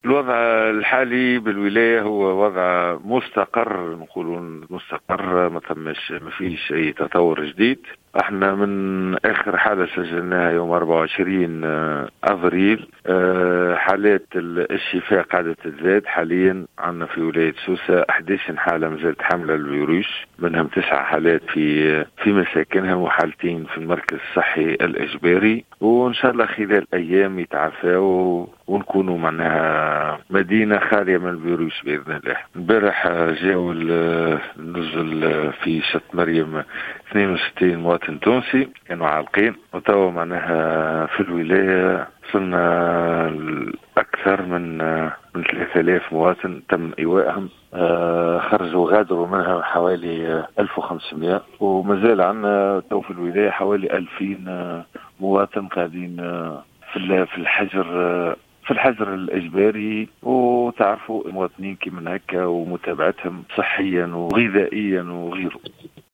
المدير الجهوي للصحة بسوسة